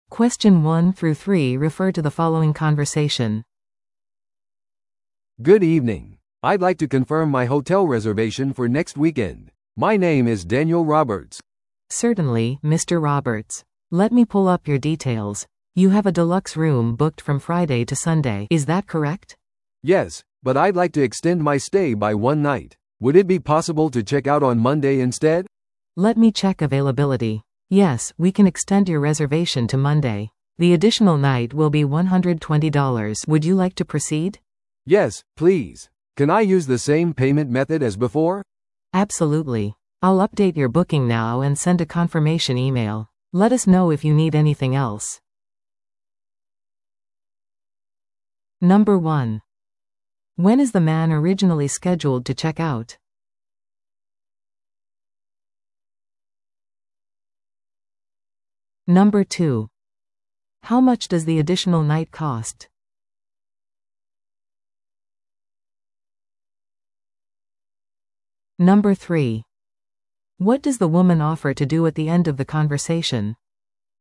No.1. When is the man originally scheduled to check out?
No.3. What does the woman offer to do at the end of the conversation?